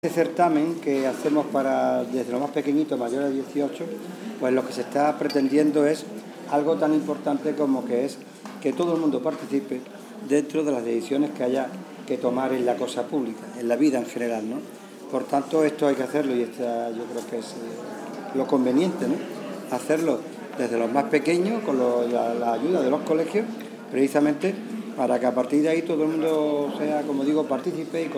Ya se conocen los relatos ganadores del sexto certamen ‘La Participación Ciudadana, un cuento posible’, que convoca el servicio de Participación Ciudadana de Diputación. Los cuentos han sido leídos por sus autoras, en un acto celebrado en el Palacio Provincial y presidido por el vicepresidente primero, José María Román y la diputada de Desarrollo Democrático, Lucía Trujillo.